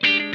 MISC R 2 HIT.wav